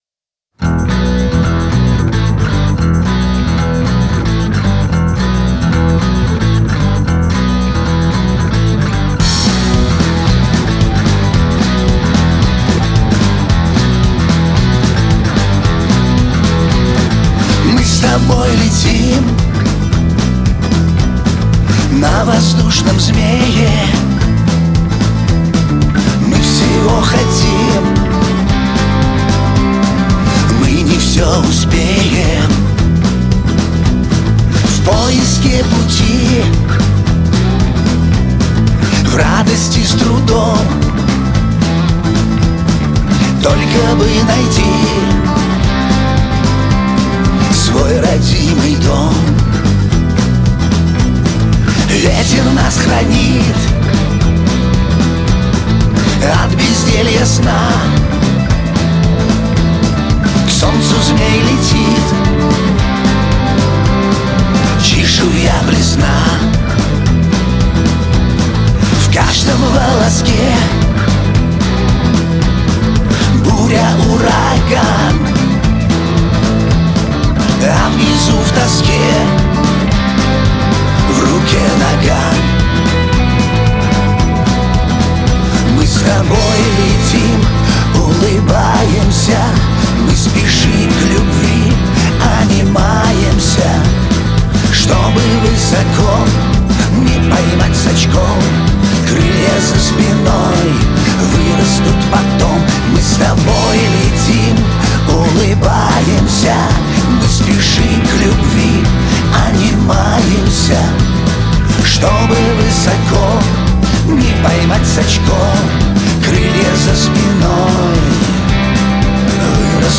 Жанр: Rock, Pop